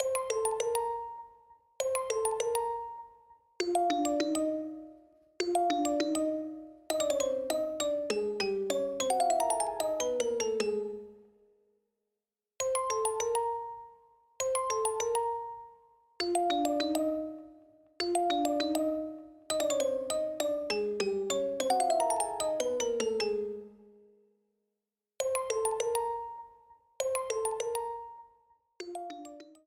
Ripped from game files